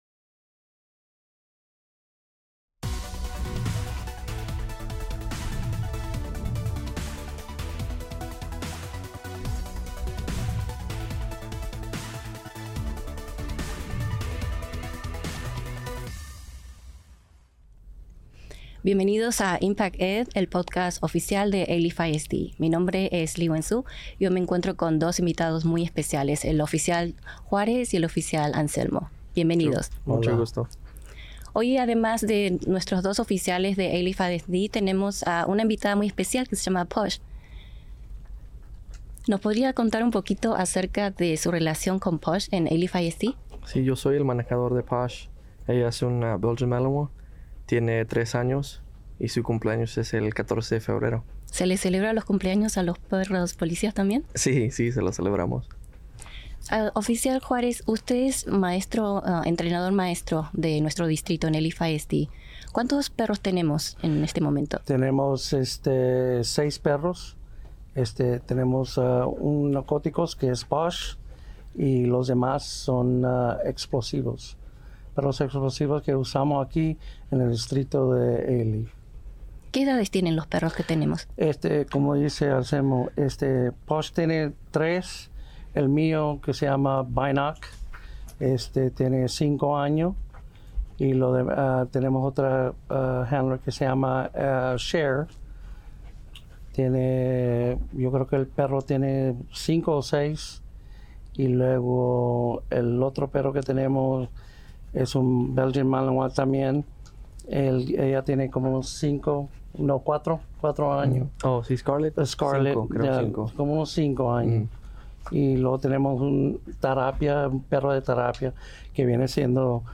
Alief ISD - Alief ISD Police K9 - Spanish Edition